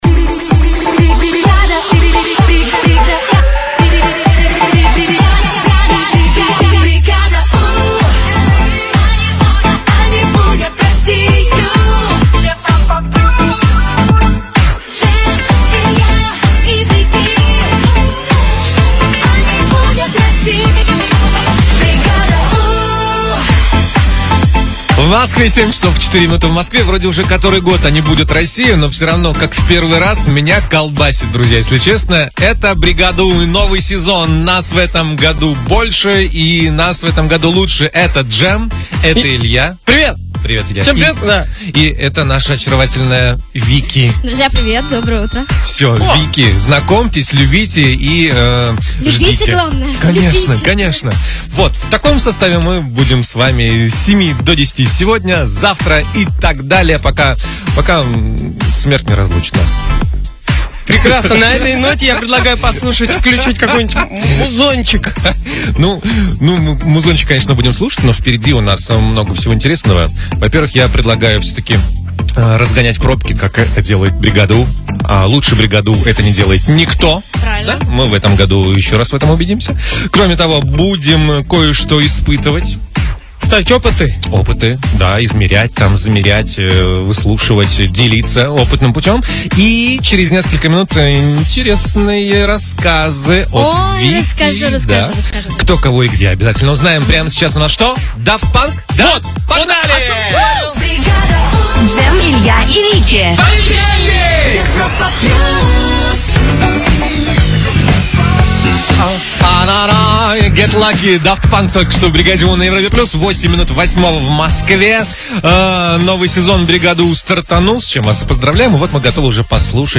Первый эфир, "Бригада У" вновь трио. Сезон 2013-2014. Запись эфира.
Утреннее шоу "Бригада У" на "Европе плюс" превратилось в трио.